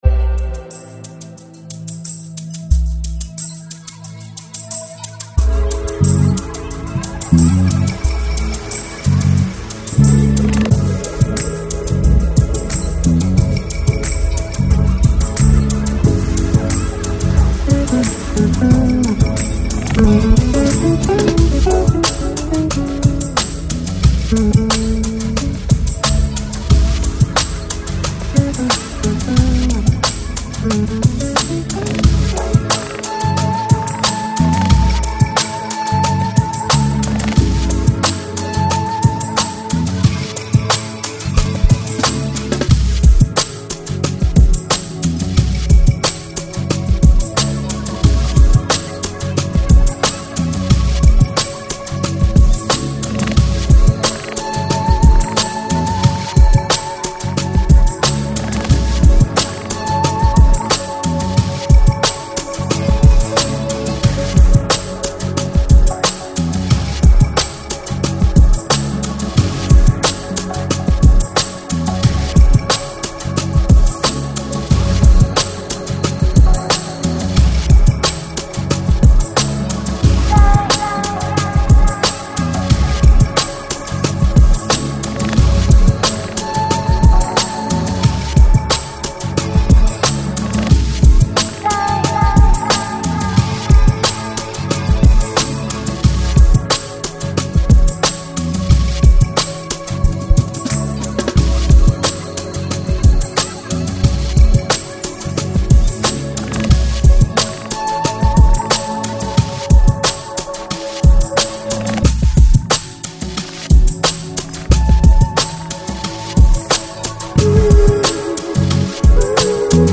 Жанр: Chillout, Lounge